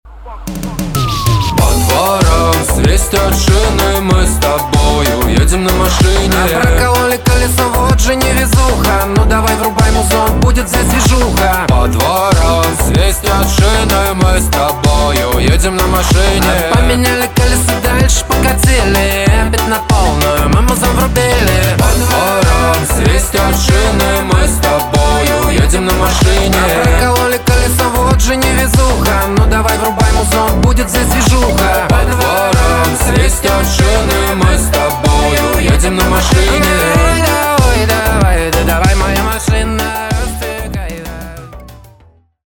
• Качество: 320, Stereo
поп
позитивные